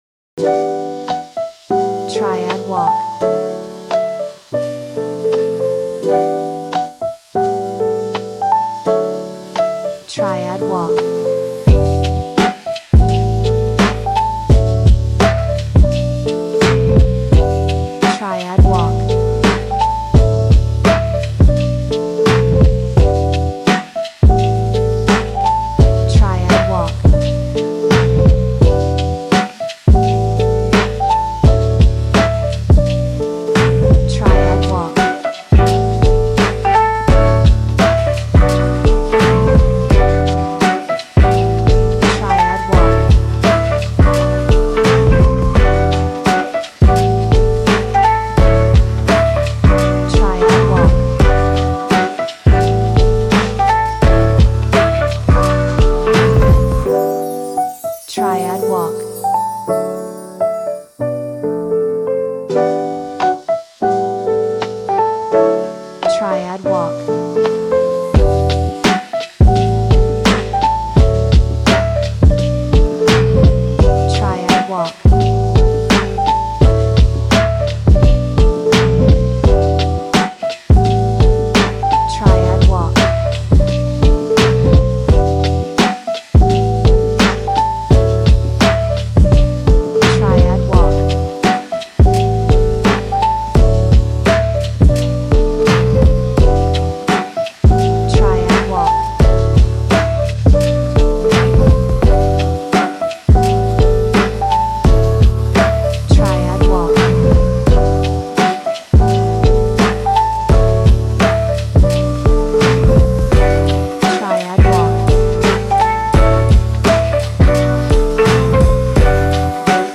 Lo-fi Hip Hop